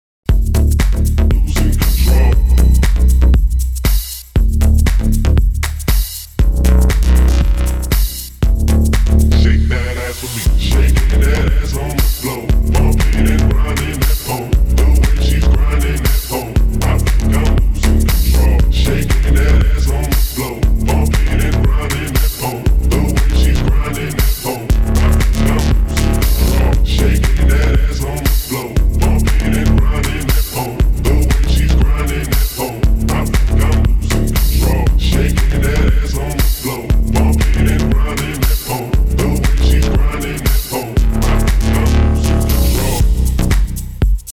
• Качество: 192, Stereo
deep house
басы
Bass House
G-House
низкий мужской голос
Стиль: Bass House, G-House